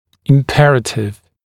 [ɪm’perətɪv][им’пэрэтив]обязательный, крайне важный